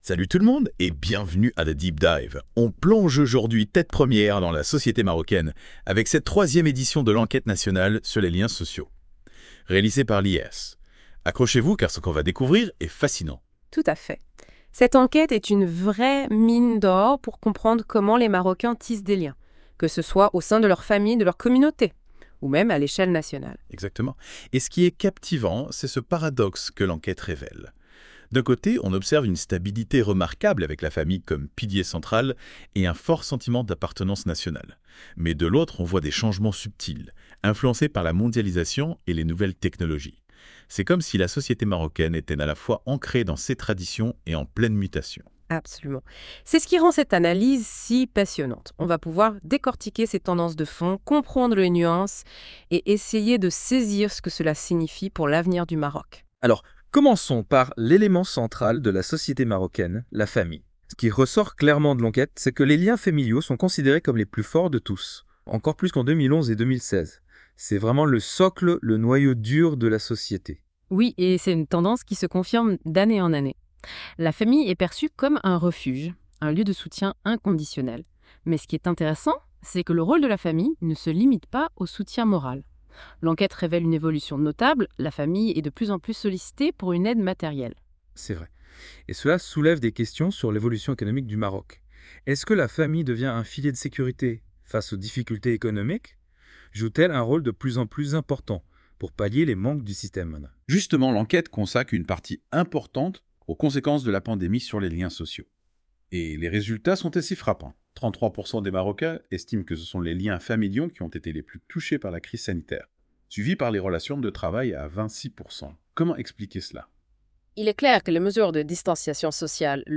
Débat (25.02 Mo) Quelles évolutions du lien social au Maroc sont mises en évidence par l'enquête ?